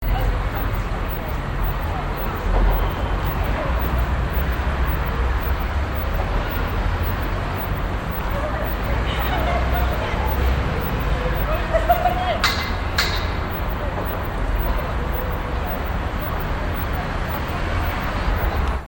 ■大人には聞こえない着信音
若者だけが聞こえる音としてテレビで少し有名になったアレ
mosquito_sound1.mp3